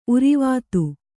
♪ urivātu